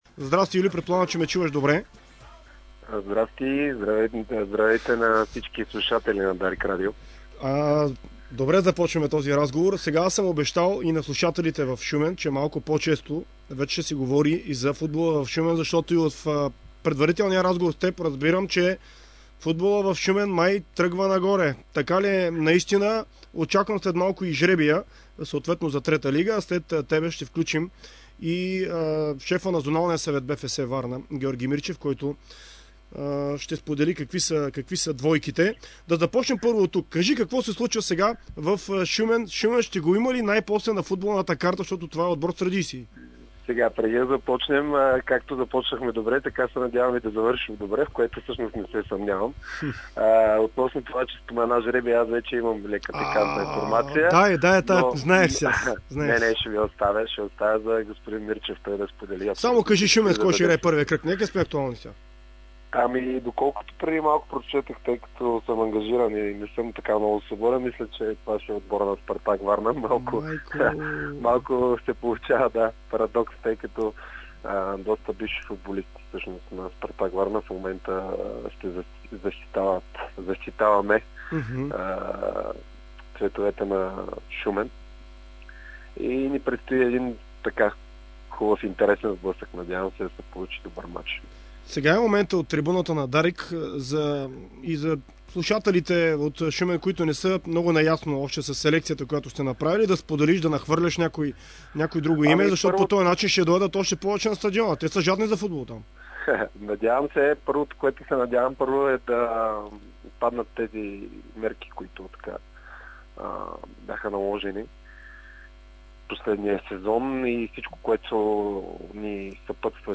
В интервю за дарик Радио